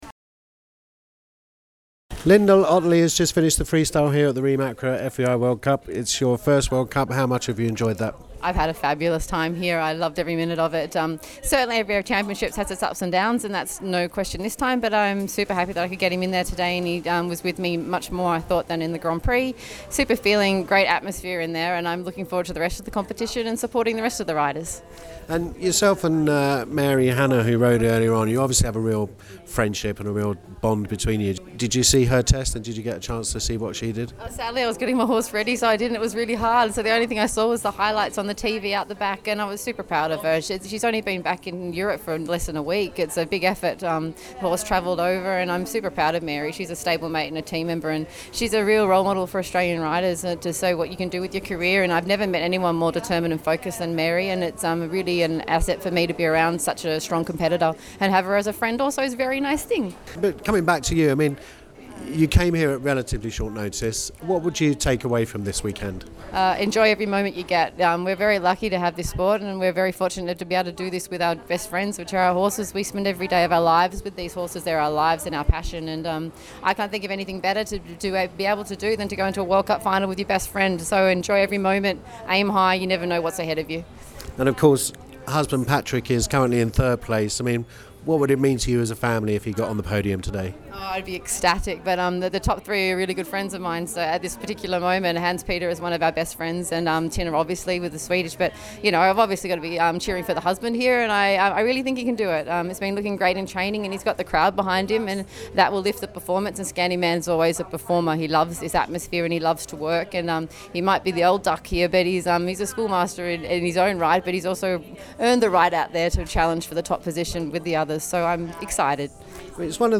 reports